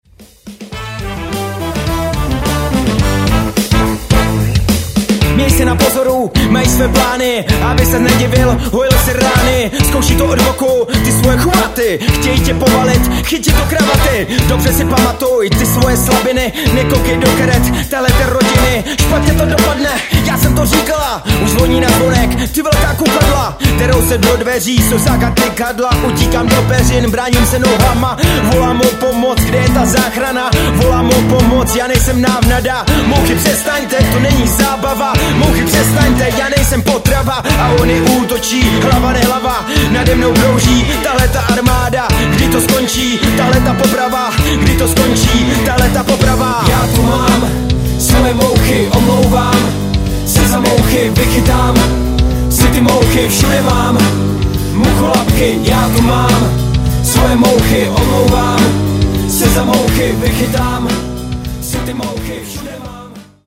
rap blues